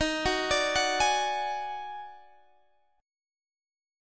D#mM7bb5 chord